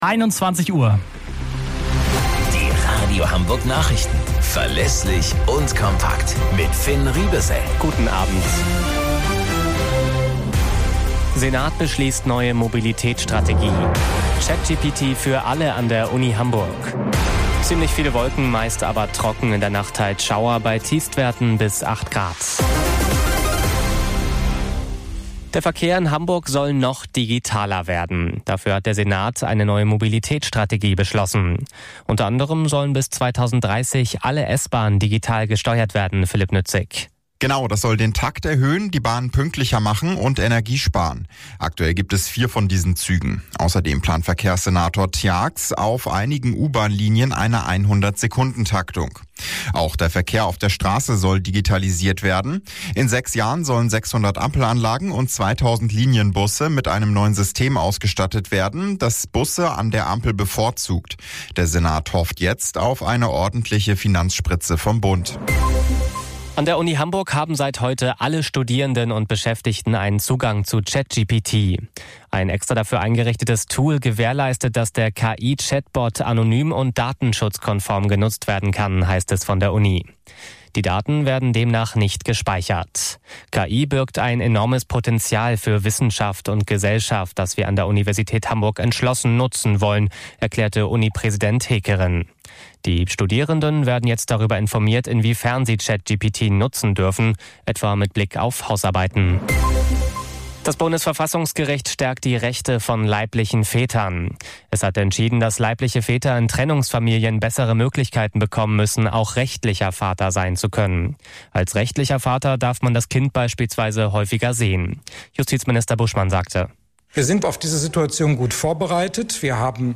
Radio Hamburg Nachrichten vom 09.04.2024 um 21 Uhr - 09.04.2024